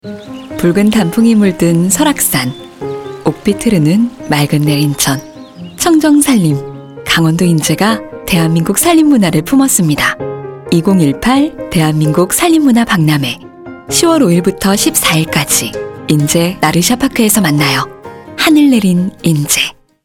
성우샘플